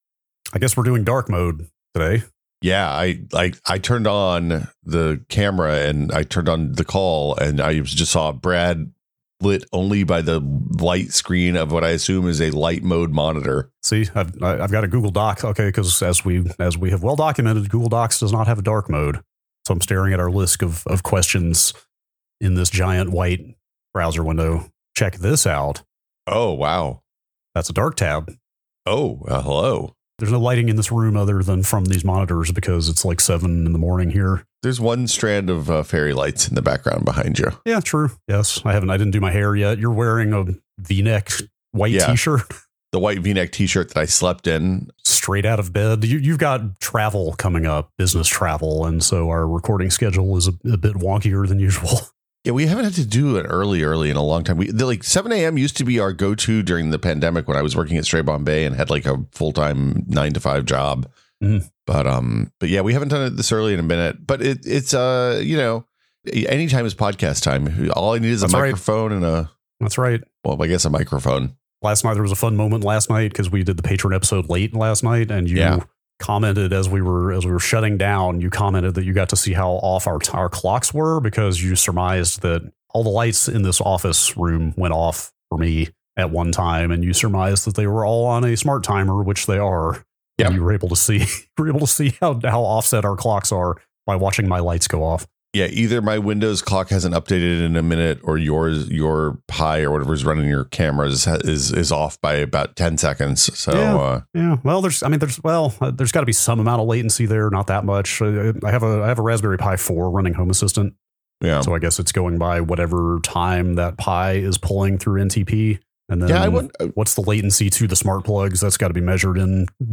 Come for the long-form conversations about virtual reality, space travel, electric cars, refresh rates, and a whole lot more.